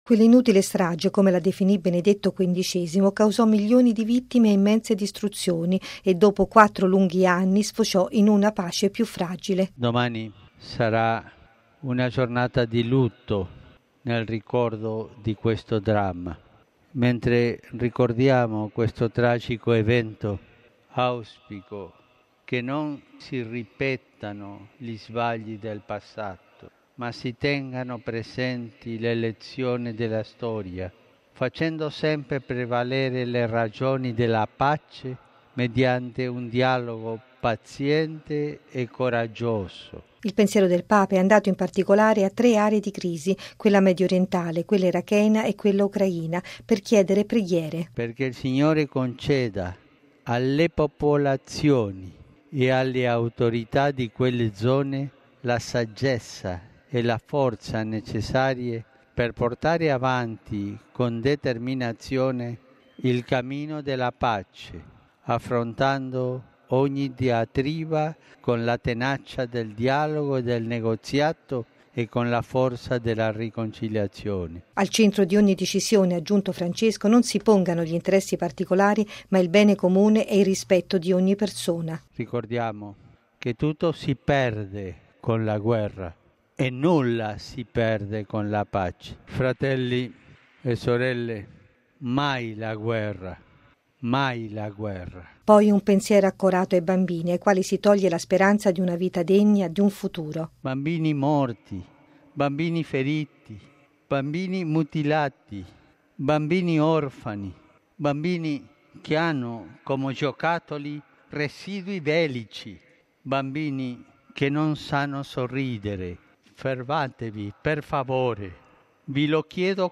Il Papa all’Angelus, ricorda lo scoppio della Prima guerra mondiale, di cui domani ricorre il 100.mo anniversario, invocando che non si ripetano gli sbagli del passato e si persegua la pace nel dialogo paziente e coraggioso. Pregando, poi, per le crisi in Medio Oriente, in Iraq e in Ucraina, implora: “fermatevi, per favore!”.